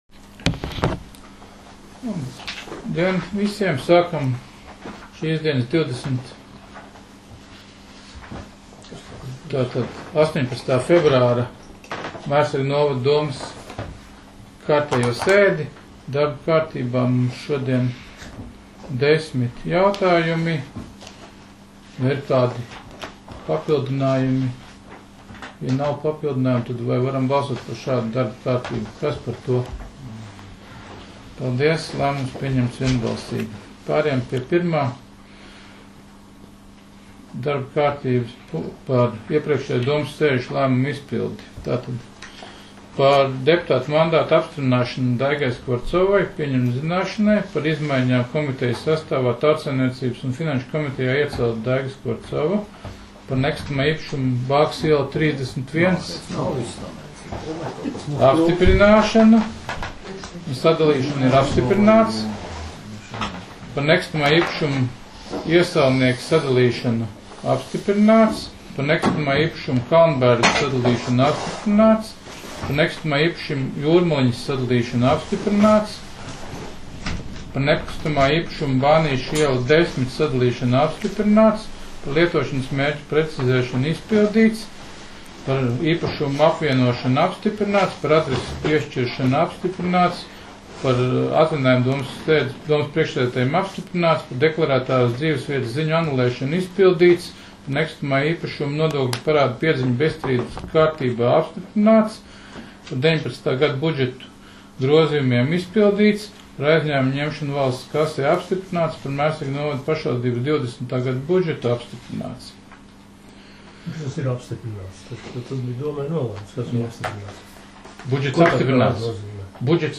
Mērsraga novada domes sēde 18.02.2020.